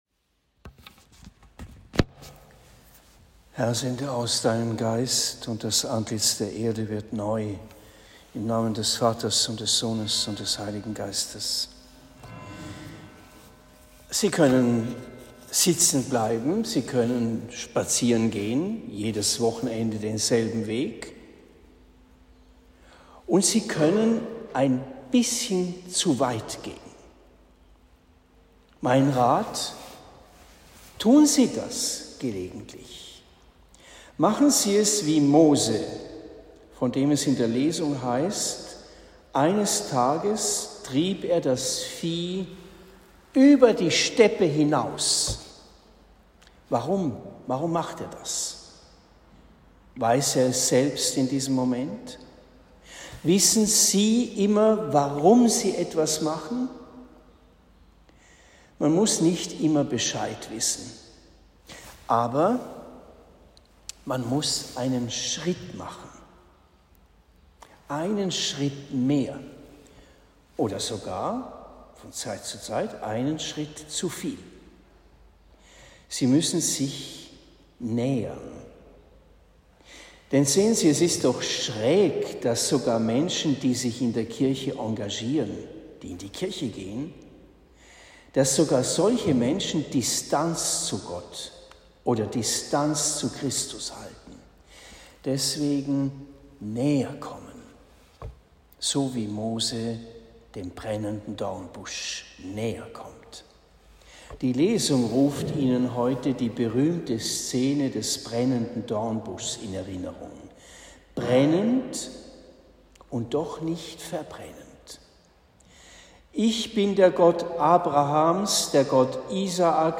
Predigt in Rettersheim am 19. Juli 2023